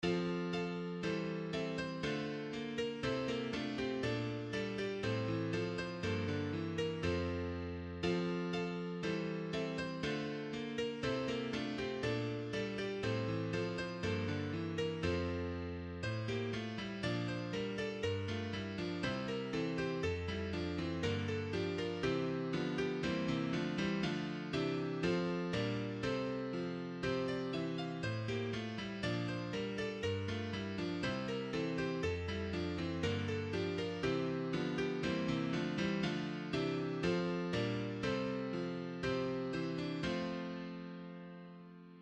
• Zweites Gemeindelied: Hört, der Engel helle Lieder (EG 54, 1–3)